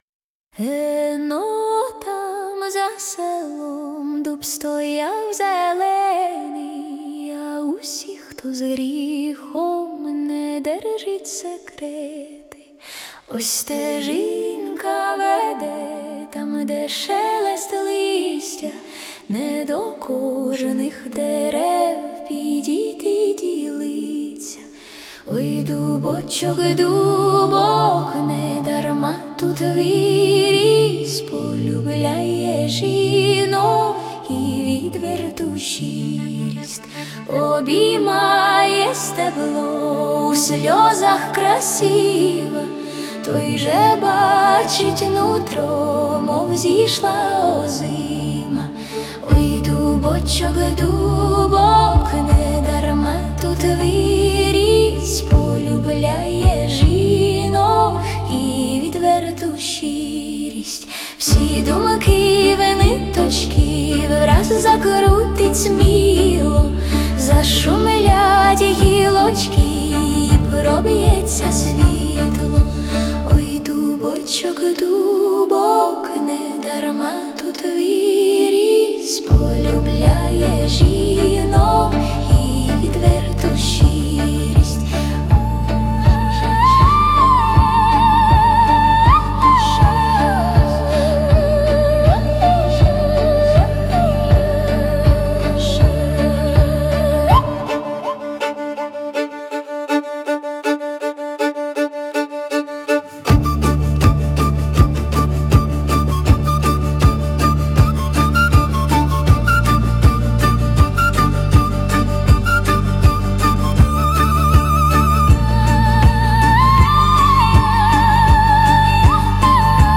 12 Весела пісня.
Грайлива така пісенька.